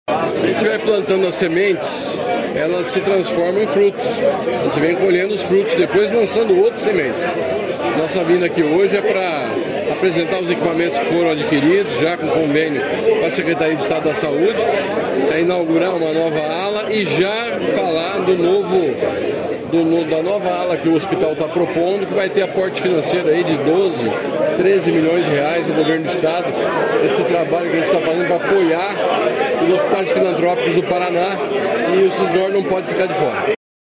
Sonora do secretário da Saúde, Beto Preto, sobre a autorização de R$ 12 milhões em novos investimentos para a saúde de Campo Mourão